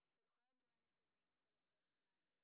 sp28_street_snr0.wav